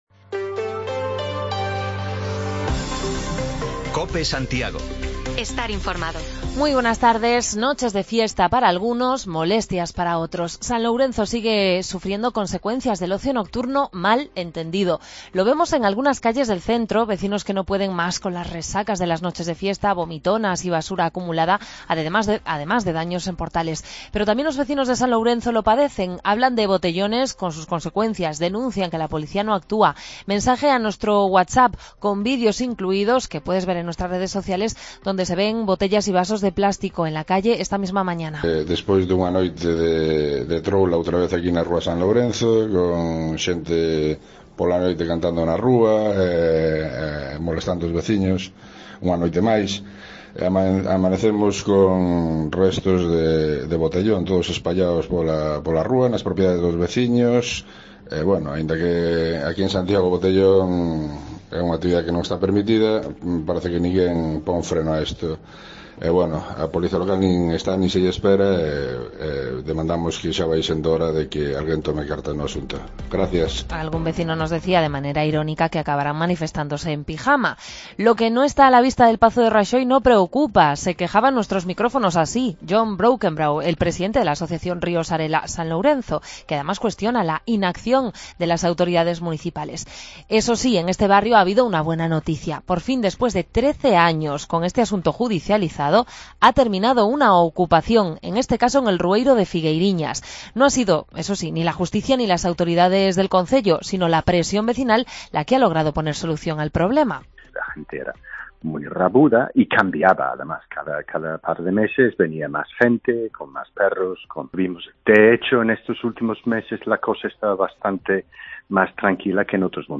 Bajamos los micrófonos de Cope a la calle para recoger preguntas y sugerencias de la ciudadanía con la vista puesta en las próximas elecciones de mayo y en el Barbanza, celebramos el 90 cumpleaños de la compañía de teatro Airiños